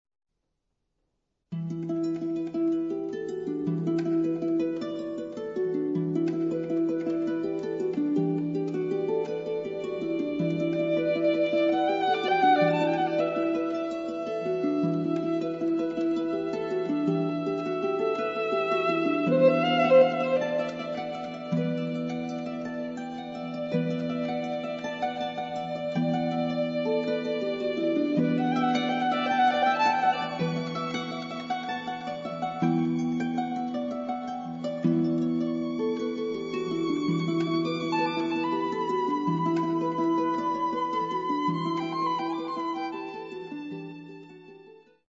Counter tenor
Electronics
Harp
Trumpet
Violin